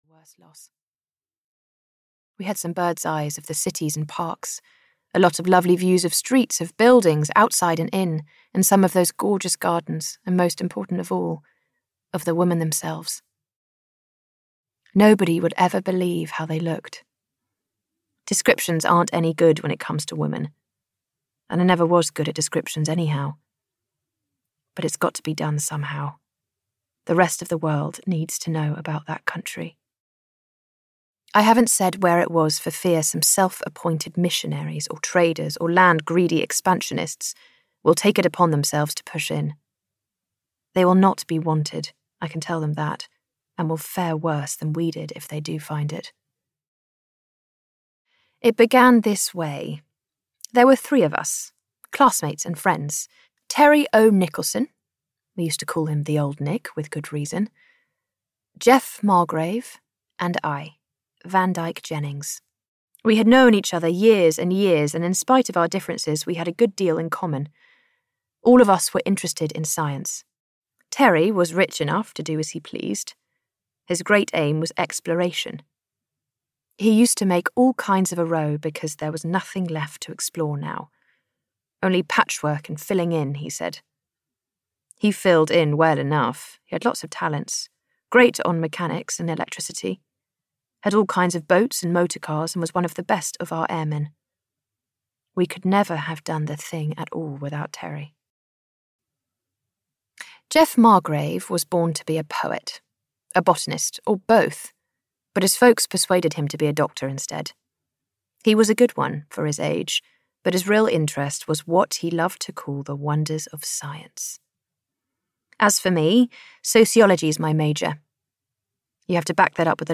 Herland (Premium) (EN) audiokniha
Ukázka z knihy
Narrated by acclaimed actress Rosy McEwen.-Feminist icon Charlotte Perkins Gilman, paved the way for women writers such as Alice Walker and Sylvia Plath, with her thought-provoking work.